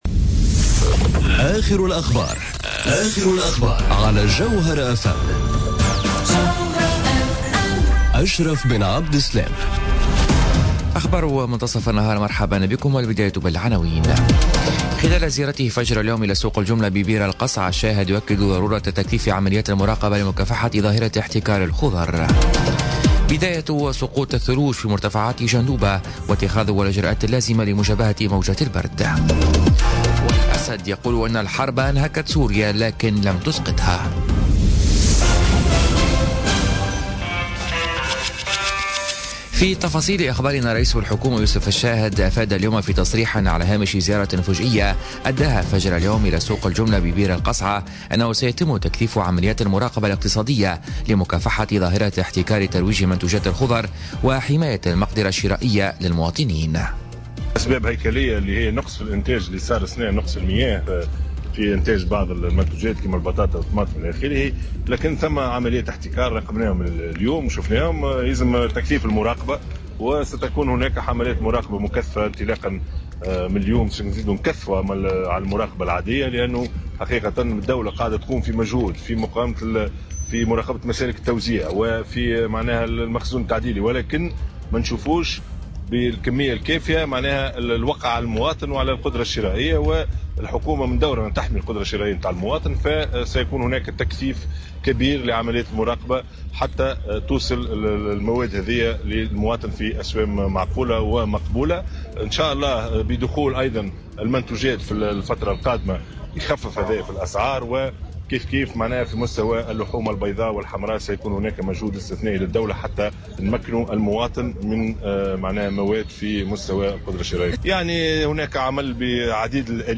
نشرة أخبار منتصف النهار ليوم الإربعاء 15 نوفمبر 2017